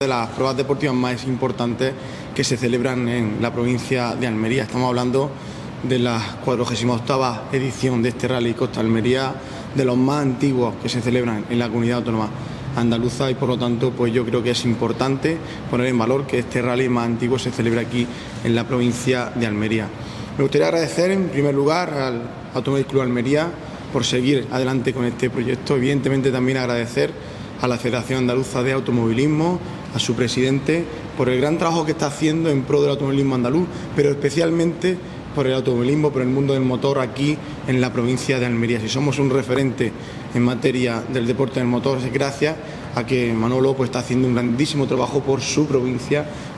16-08_rallye__diputado_out.mp3